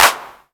049 - Clap-2.wav